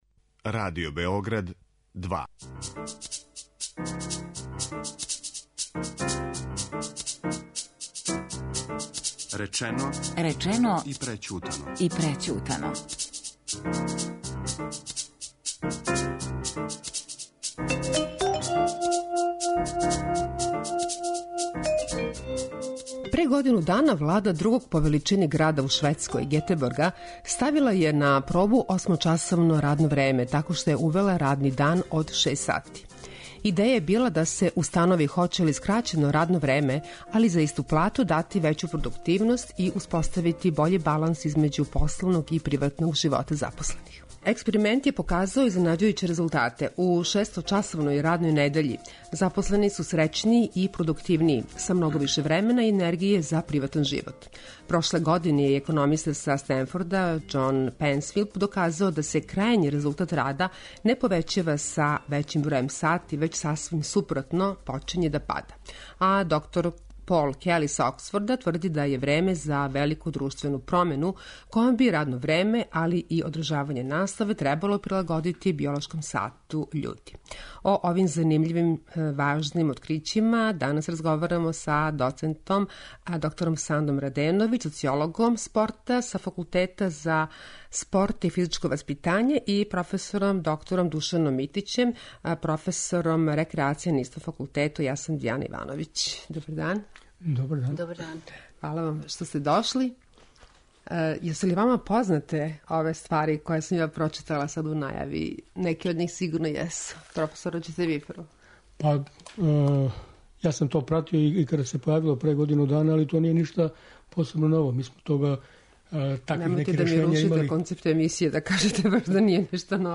О овим занимљивим и важним открићима данас разговарамо